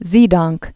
(zēdŏngk, -dôngk)